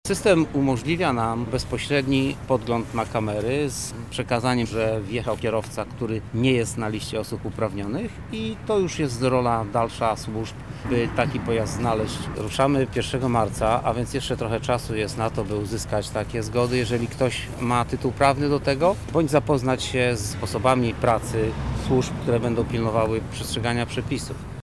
Więcej o funkcjonowaniu systemu mówi Jacek Kucharczyk, Komendant Straży Miejskiej Miasta Lublin: